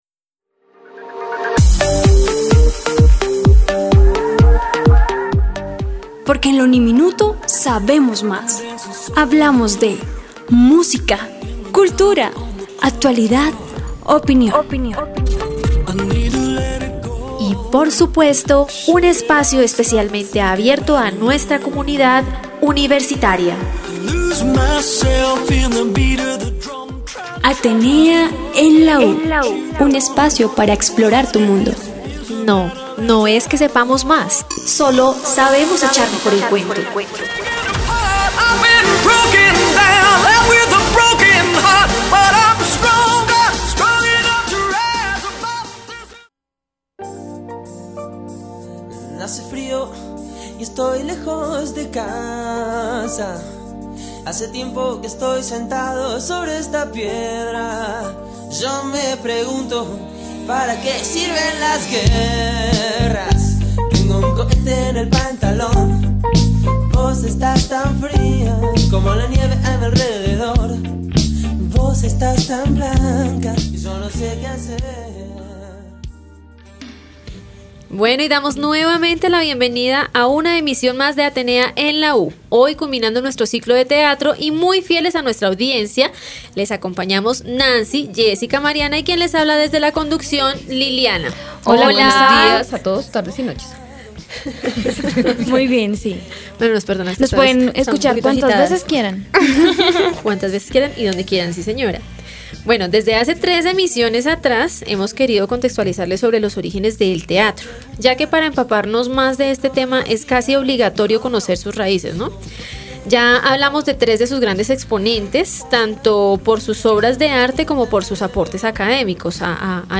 Dando cierre a nuestro ciclo dedicado al Teatro, no podíamos pasar por alto recordar a aquella mujer, a quien le debemos lo que hoy en día, es el Teatro en Colombia, aquella de cabellos rojos, voz ronca y amplia sonrisa quien fuese la celestina de dicho movimiento artístico; hablamos de Fanny Mikey, su vida y obra resumida en nuestra emisión #23, ambientada con el viejo pero confiable rock Argentino.